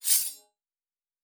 Fantasy Interface Sounds
Weapon UI 09.wav